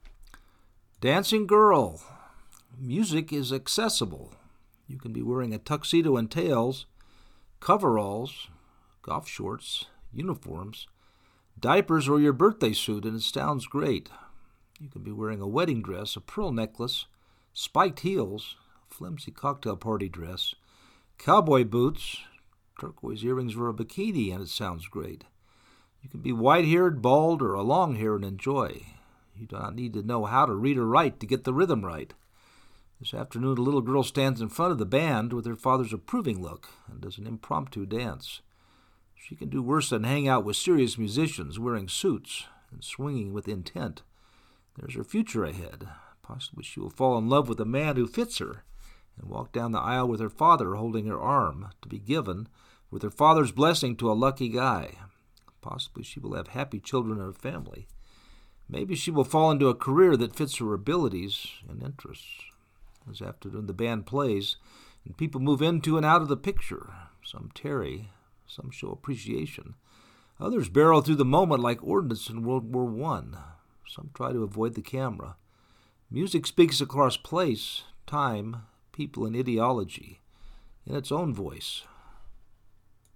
This afternoon the band plays and people move into and out of the picture.